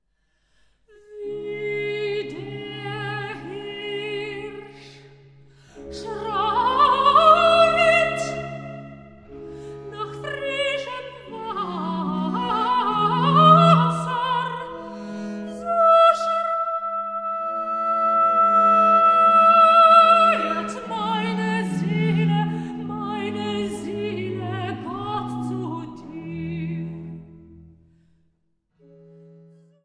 Kirchweihkantaten
Sopran
Orgel und Leitung